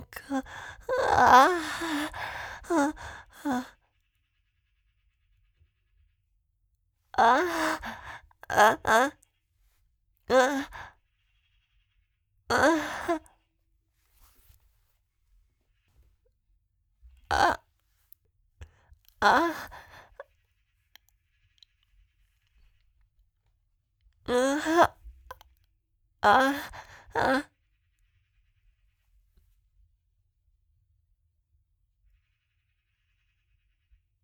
These birds really do have a mind of their own fkfkfkfk
The bird was trying to copy you